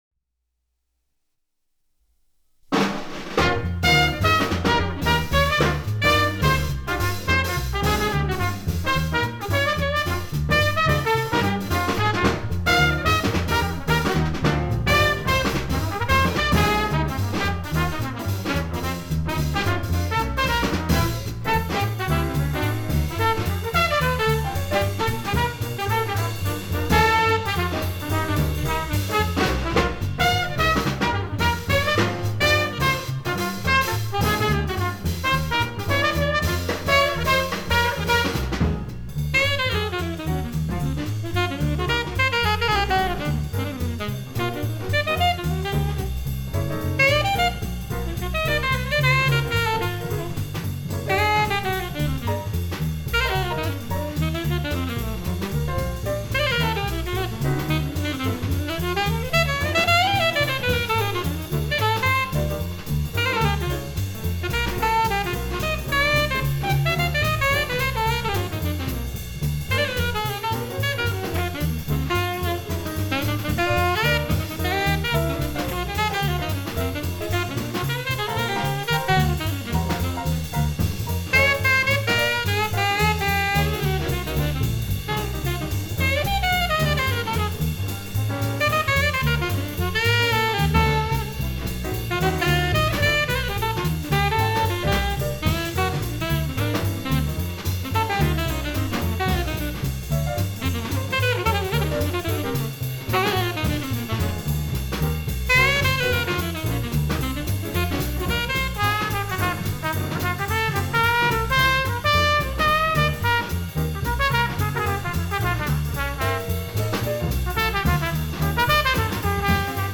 West Coast jazz
trumpet and valve trombone
alto saxophone
piano